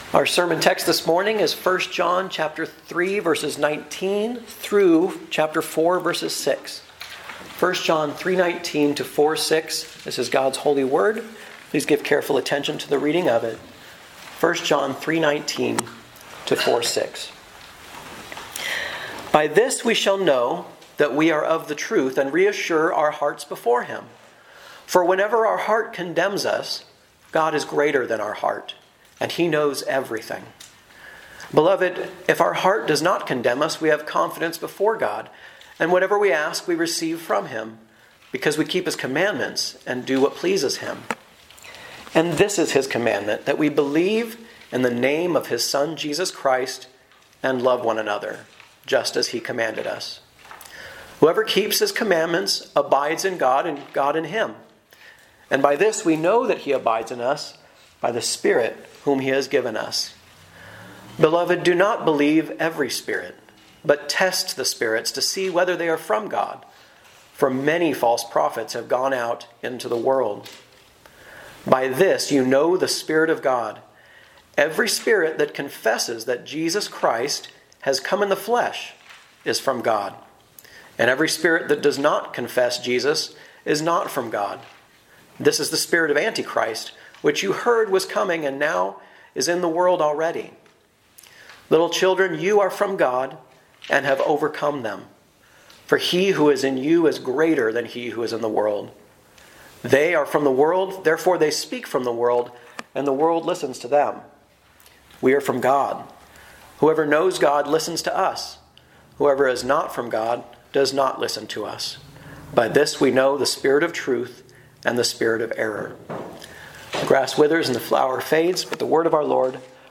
A message from the series "Guest Preacher."